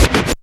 RHYTHM SCRTC.wav